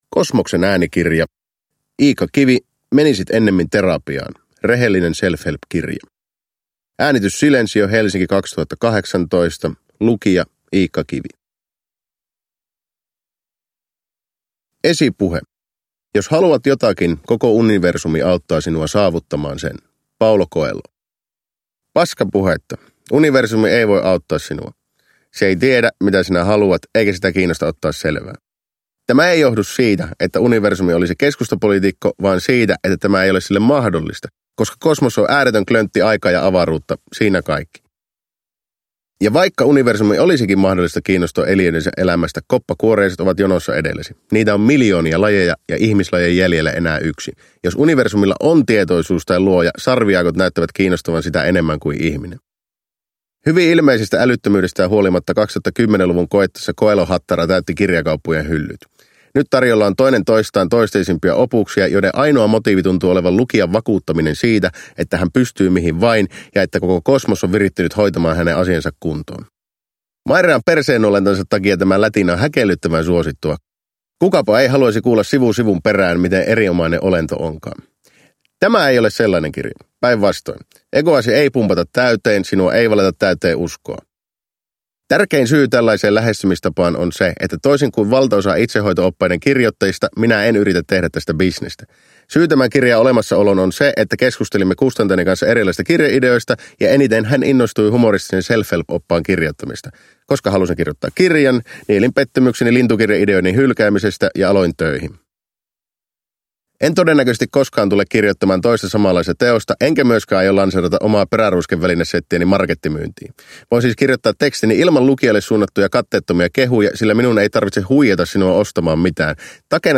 Menisit ennemmin terapiaan – Ljudbok – Laddas ner
Uppläsare: Iikka Kivi